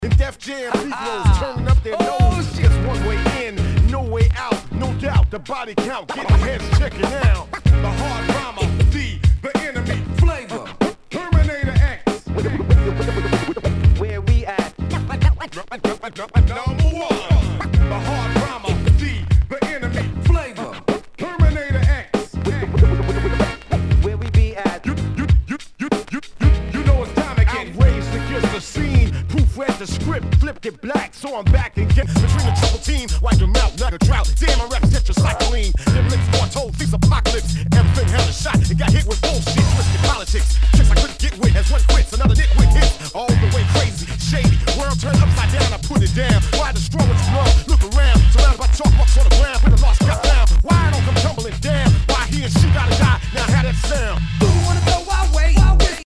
HIPHOP/R&B
盤に傷多数あり、全体に大きくチリノイズが入ります
[VG-] 傷や擦れが目立ち、大きめなノイズが出る箇所有り。